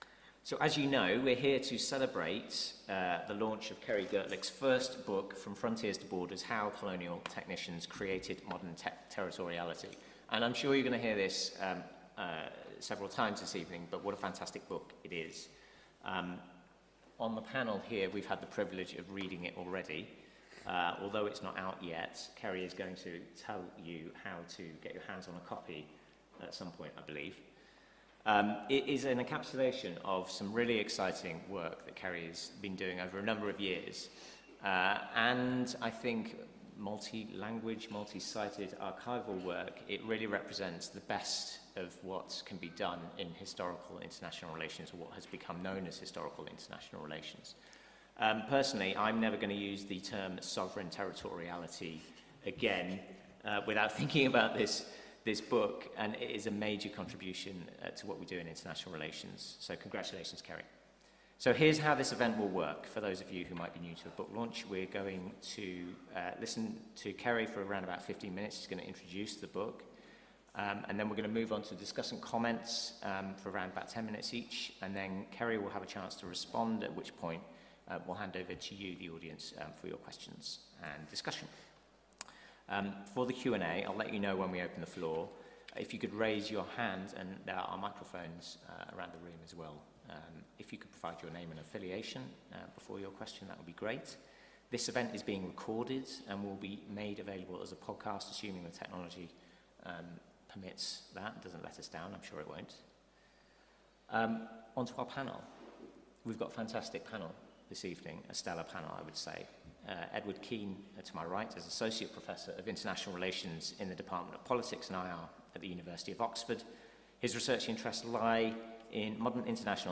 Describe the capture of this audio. Free public event at LSE